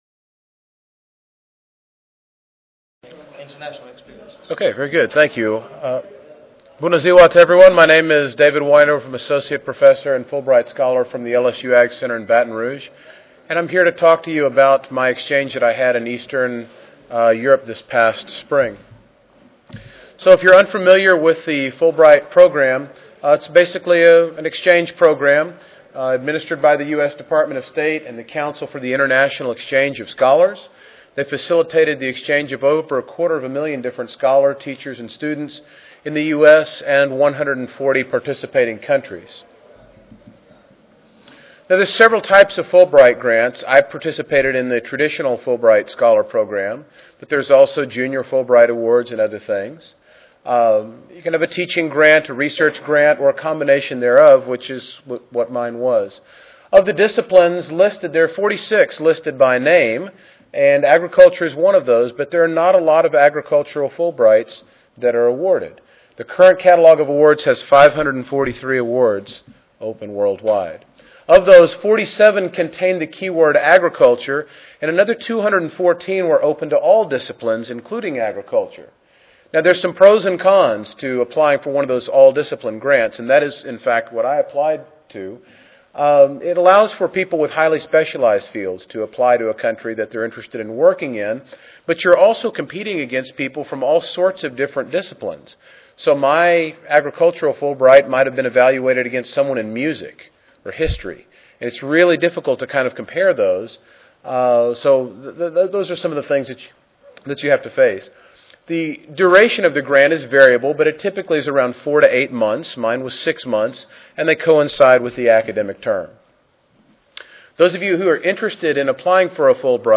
Louisiana State University AgCenter Recorded Presentation Audio File